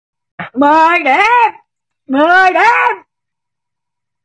Thể loại: Câu nói Viral Việt Nam
Description: Tải meme 10 điểm mp3 edit video được trích xuất từ video nói mười điểm, mười điểm hài hước trên Ytb.